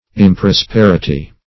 Search Result for " improsperity" : The Collaborative International Dictionary of English v.0.48: Improsperity \Im`pros*per"i*ty\, n. [Cf. F. improsp['e]rit['e].]